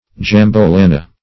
Meaning of jambolana. jambolana synonyms, pronunciation, spelling and more from Free Dictionary.
Search Result for " jambolana" : The Collaborative International Dictionary of English v.0.48: Jambolana \Jam`bo*la"na\, n. [Cf. Pg. jambol[~a]o a kind of tropical fruit.]